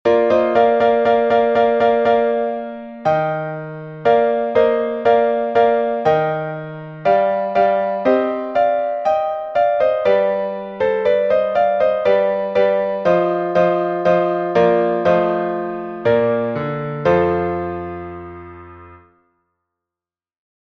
Глас 4